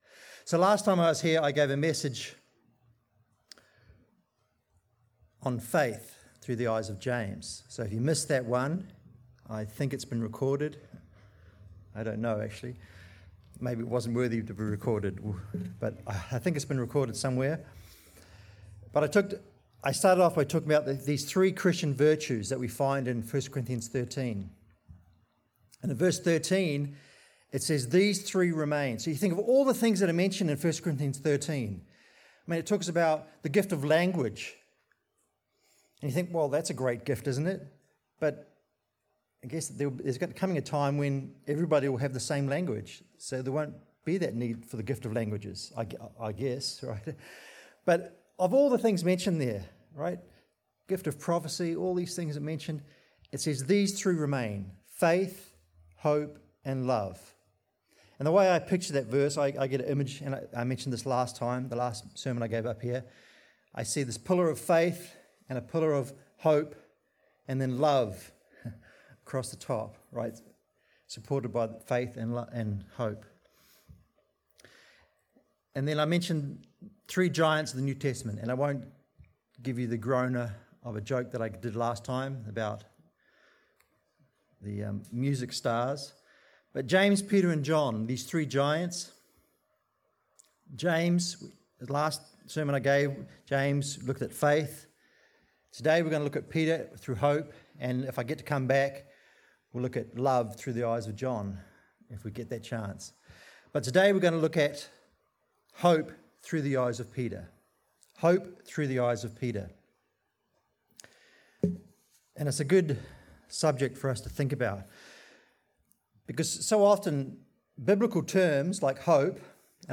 Sermons
Given in Seattle, WA